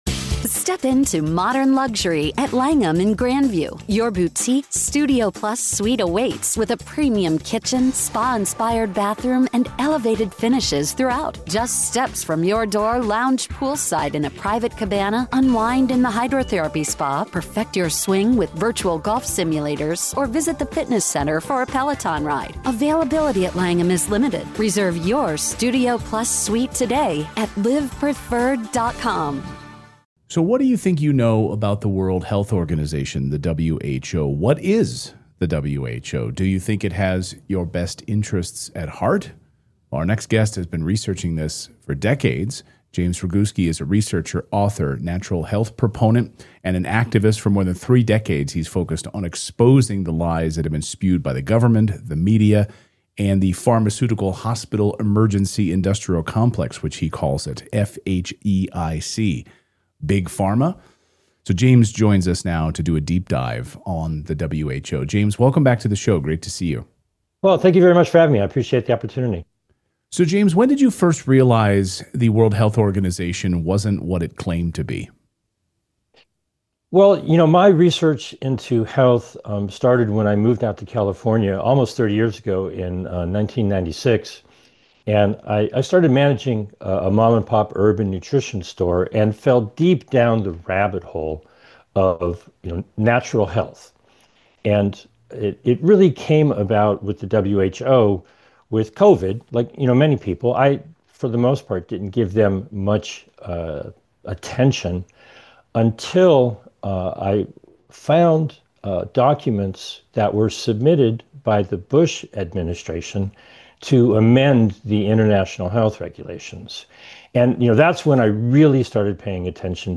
Redacted Conversation